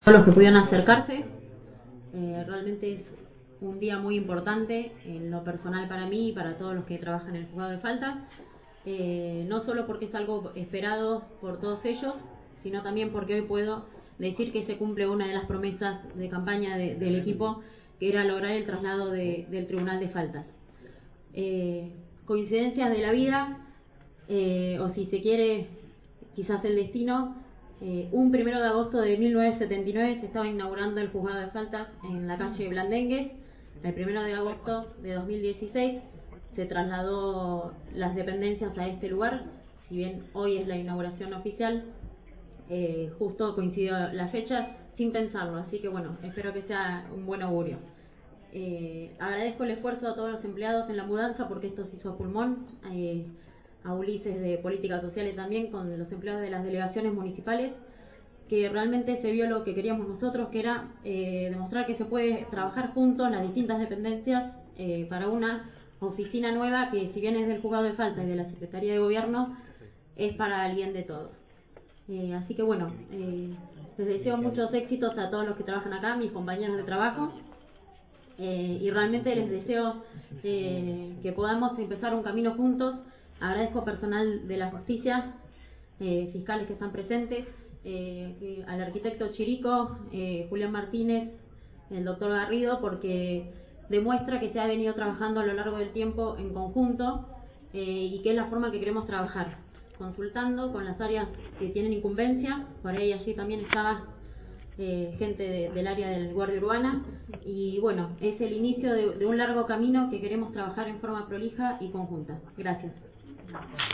El intendente municipal Héctor Gay encabezó la ceremonia de inauguración de la nueva sede del Tribunal de Faltas en Estomba